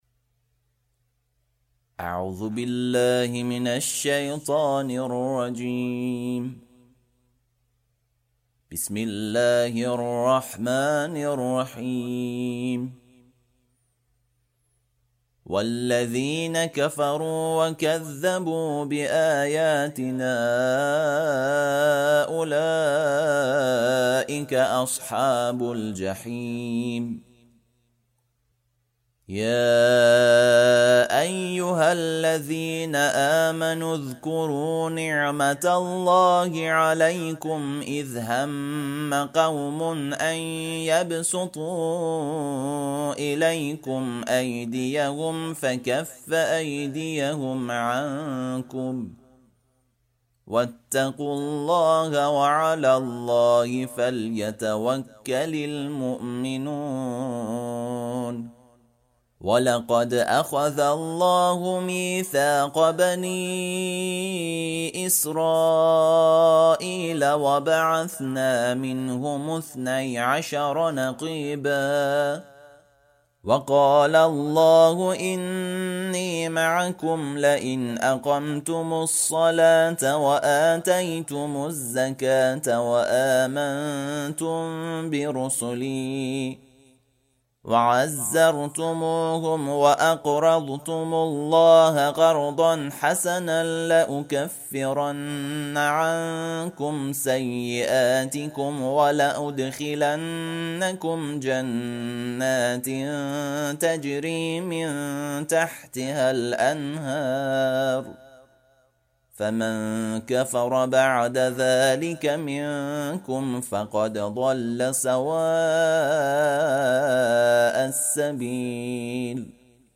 ترتیل صفحه ۱۰۹ سوره مبارکه مائده(جزء ششم)
ترتیل سوره(مائده)